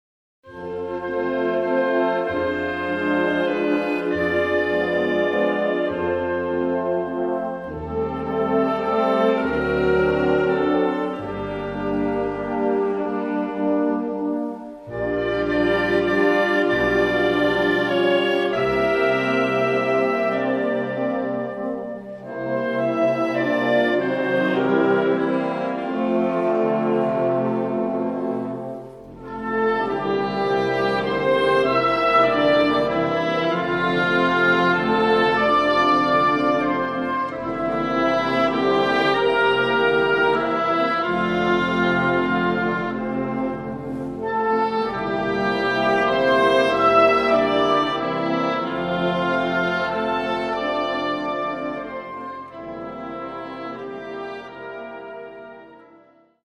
• Besetzung: Blasorchester